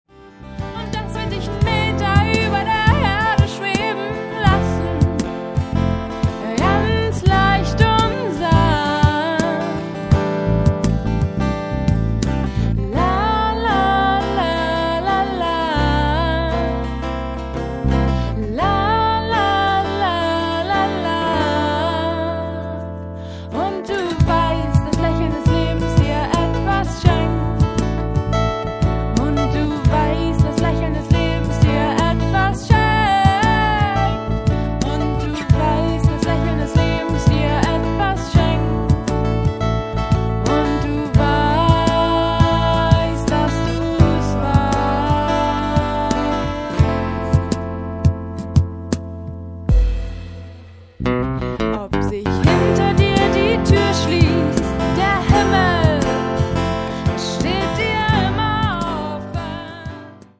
Die Itzehoer Band Hochglanz brachte Rockmusik in die Hütte.
Vor über 500 begeisterten Zuhörerinnen und Zuhörern zeigte die Band das breite Spektrum ihres Repertoires.